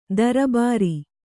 ♪ darabāri